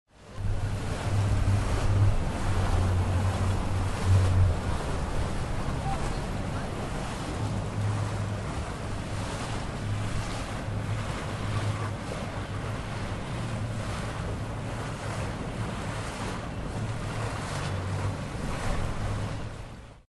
Шум лодки, скользящей по воде (с внутренней записью)